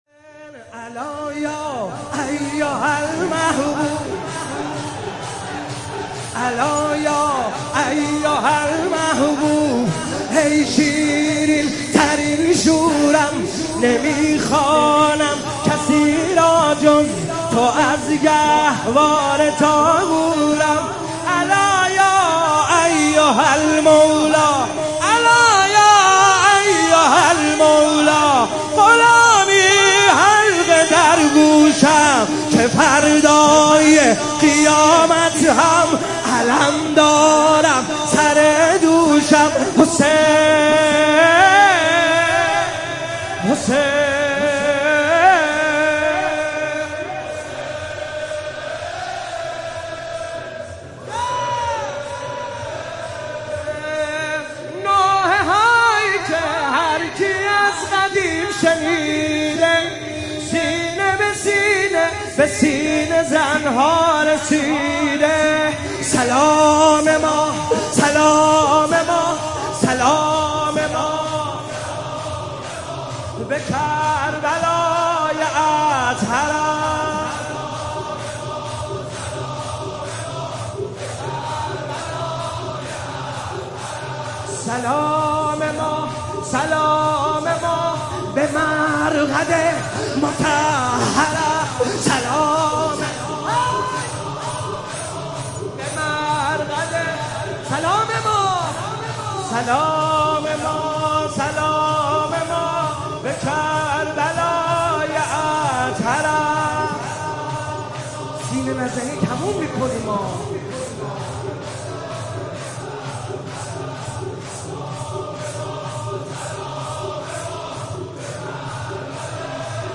مداحی جدید کربلایی حسین طاهری شب دوم محرم97 هیئت مکتب الزهرا
الا یا ایها المحبوب - شور